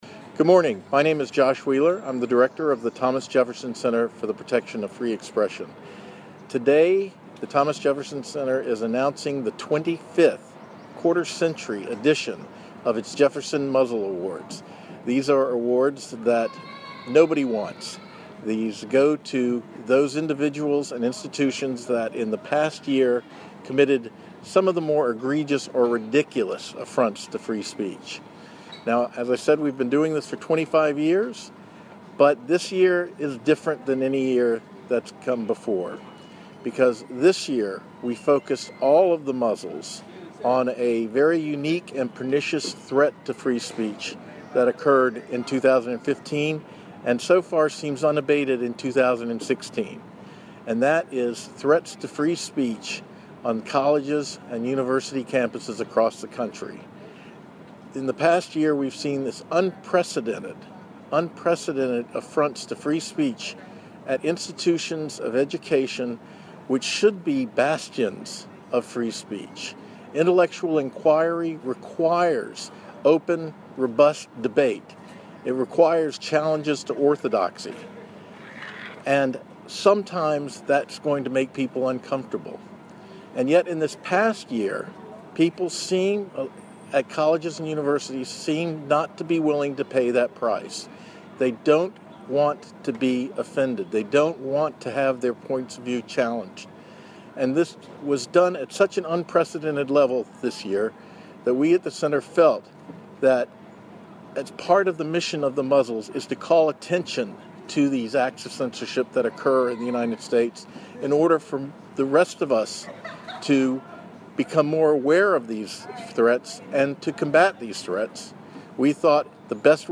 The Muzzles Turn 25: Thomas Jefferson Center news conference